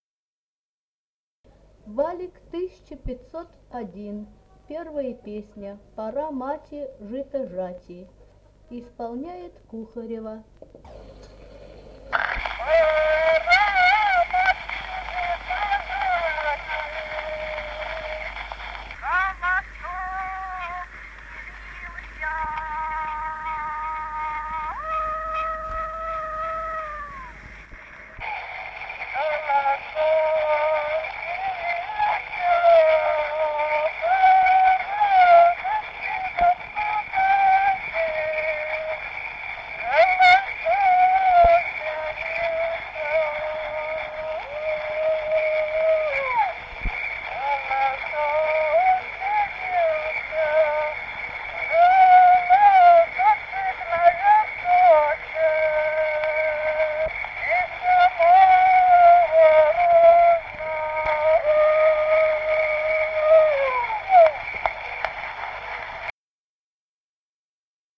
Народные песни Стародубского района «Пора, мати, жито жати», жнивная.